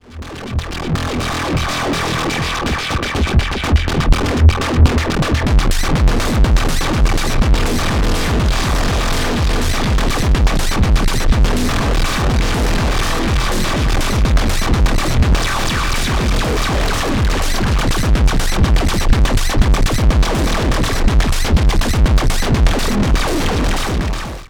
two little somethings, Digitakt with Wavetables and my DFAM samples …
(distortion and a bit delay and that tape thingy)